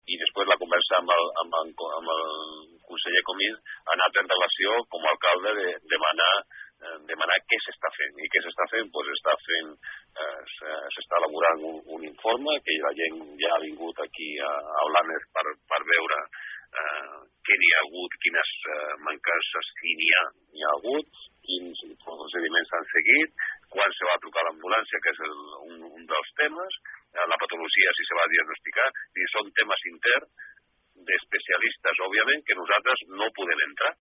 Lupiáñez, en declaracions aquesta tarda, ha defensat la tasca dels professionals sanitaris.